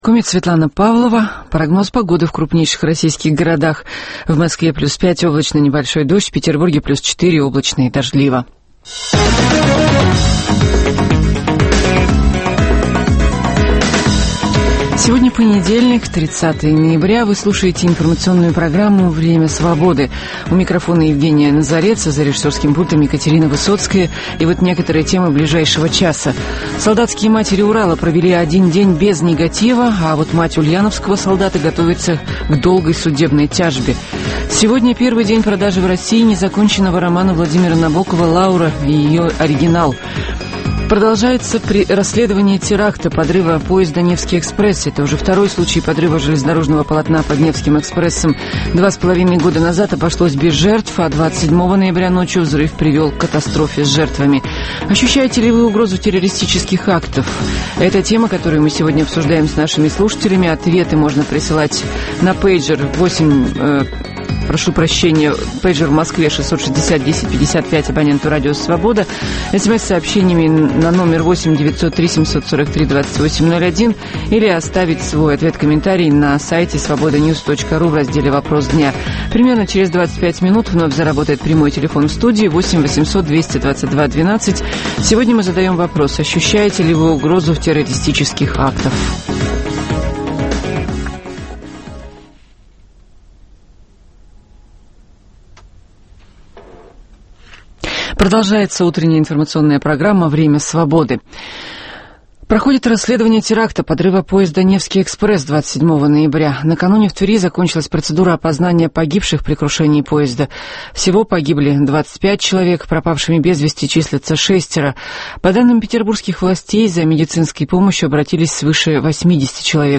С 9 до 10 часов утра мы расскажем о том, что готовит нам начинающийся день. Представим панораму политических, спортивных, научных новостей, в прямом эфире обсудим с гостями и экспертами самые свежие темы нового дня, поговорим о жизни двух российских столиц.